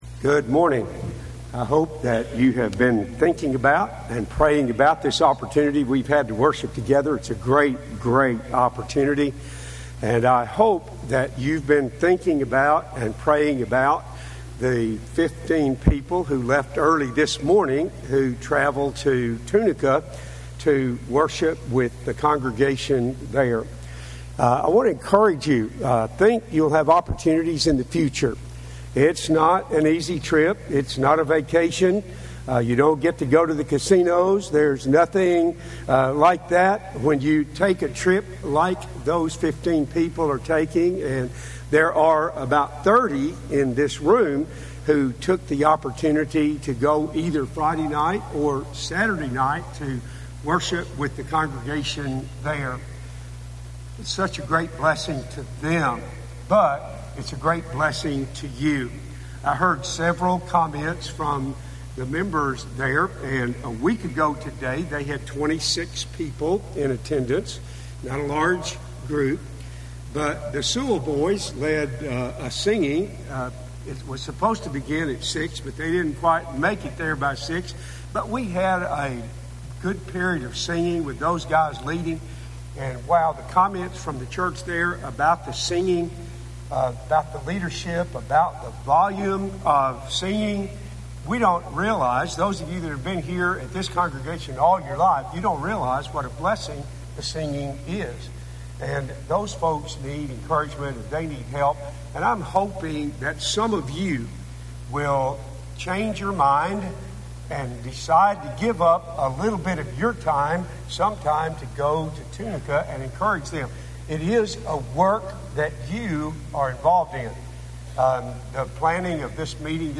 It Ain’t Over Until God Says It’s Over – Henderson, TN Church of Christ